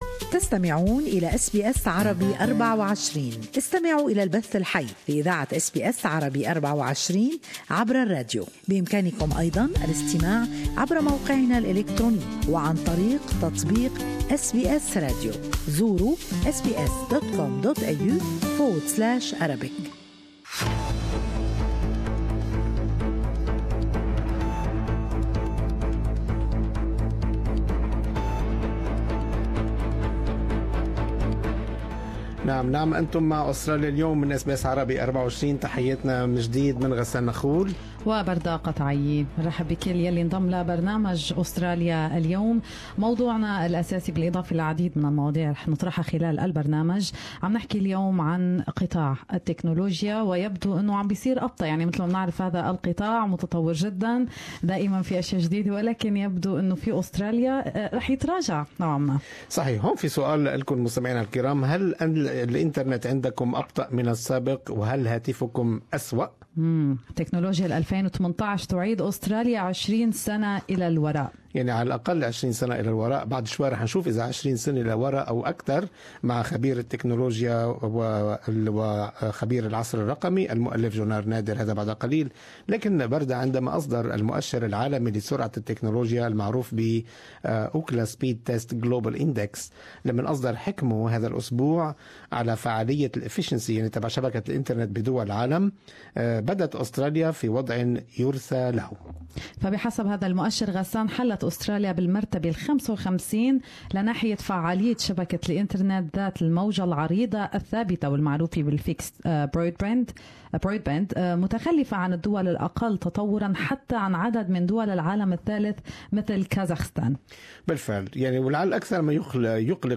Australia’s internet efficiency is behind Kazakhstan’s while Australian mobiles and iPads are exposed to hacking. More in this interview